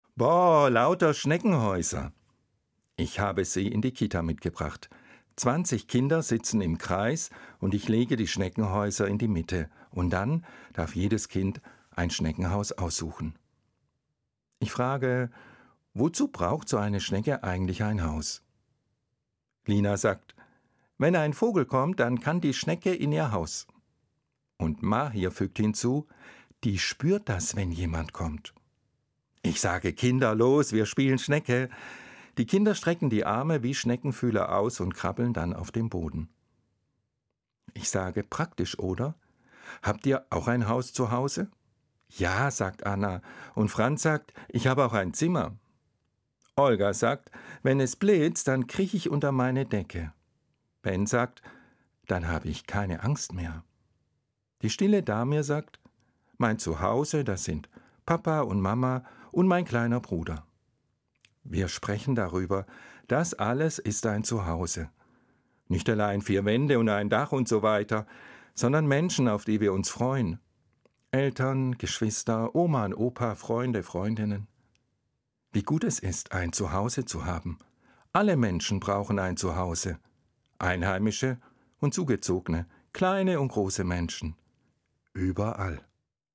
Evangelischer Pfarrer, Limburg